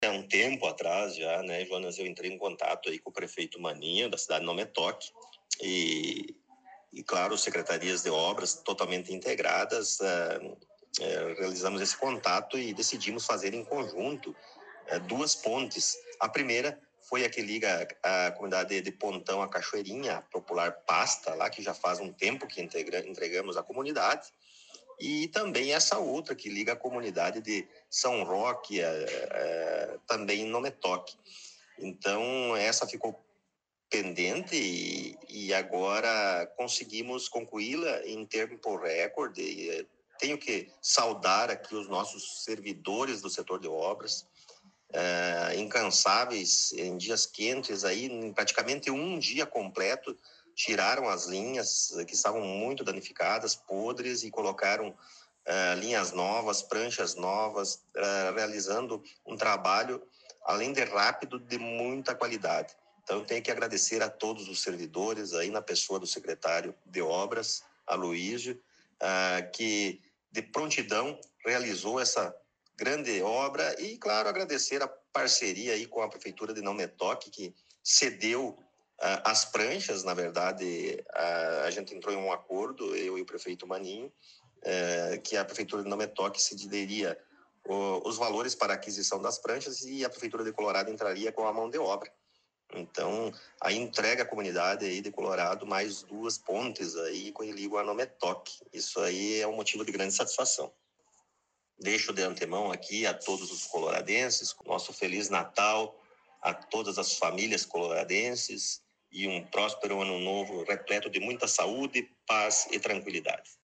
Prefeito Rodrigo Sartori concedeu entrevista
O Colorado em Foco teve a oportunidade de entrevistar mais uma vez o prefeito Rodrigo Sartori em seu gabinete.